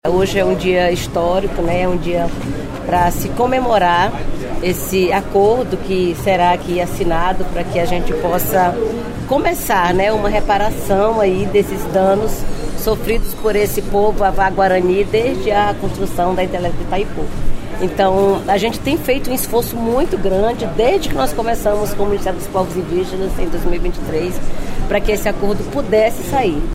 A ministra dos Povos Indígenas, Sônia Guajajara, elogiou a realização do acordo.